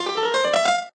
piano_scale.ogg